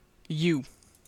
Ääntäminen
US : IPA : [ju]